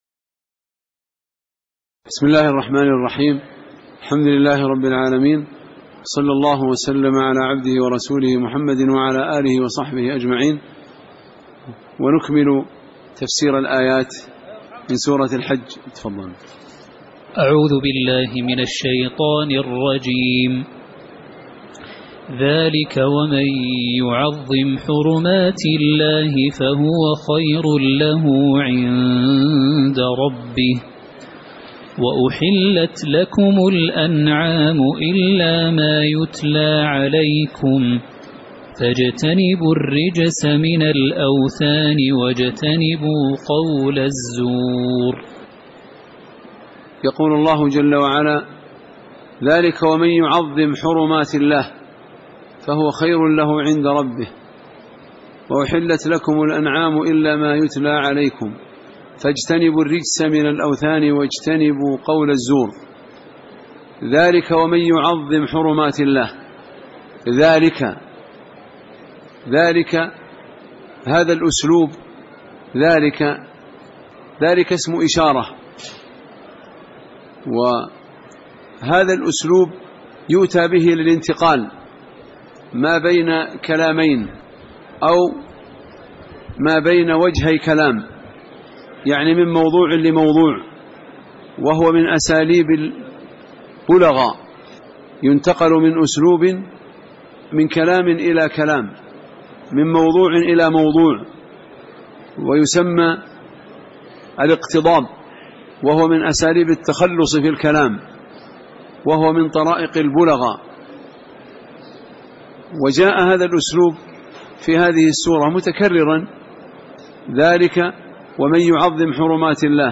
تاريخ النشر ٢٣ ذو القعدة ١٤٣٨ هـ المكان: المسجد النبوي الشيخ